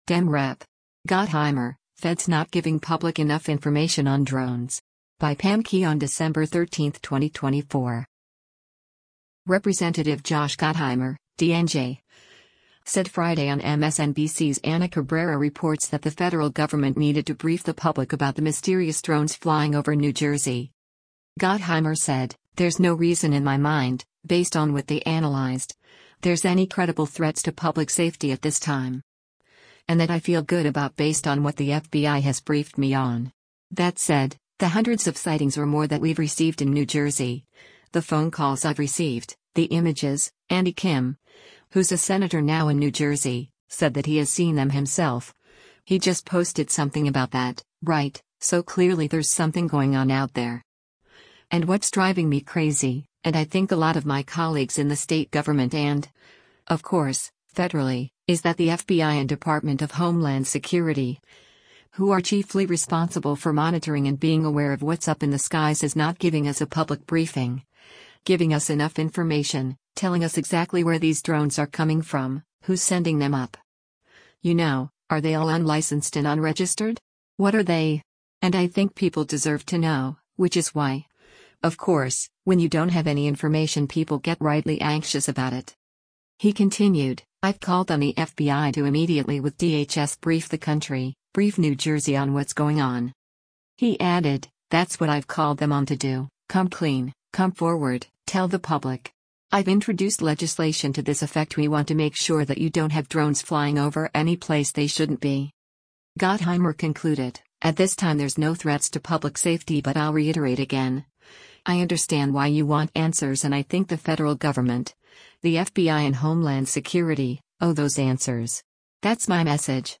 Representative Josh Gottheimer (D-NJ) said Friday on MSNBC’s “Ana Cabrera Reports” that the federal government needed to brief the public about the mysterious drones flying over New Jersey.